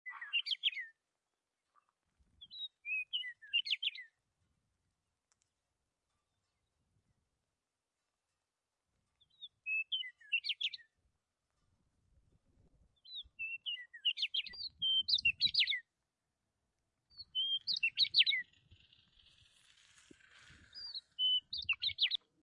Download Birds Chirping sound effect for free.
Birds Chirping